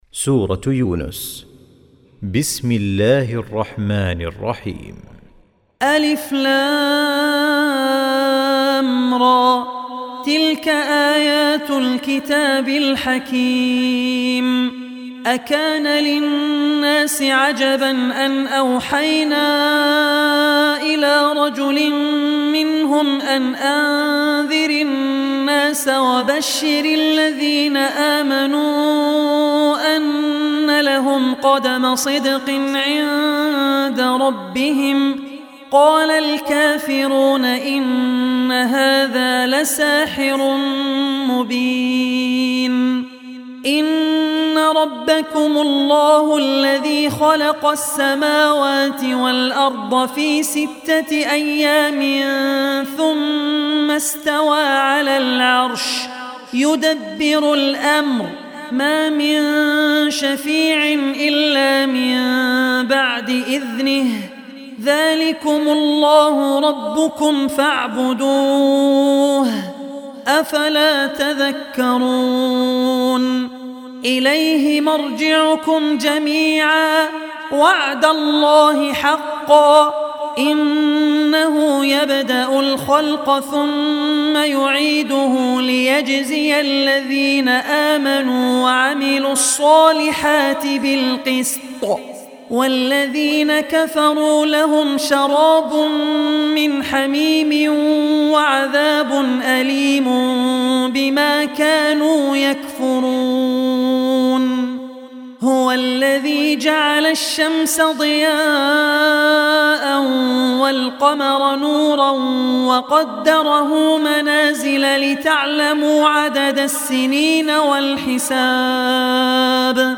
Surah Yunus Recitation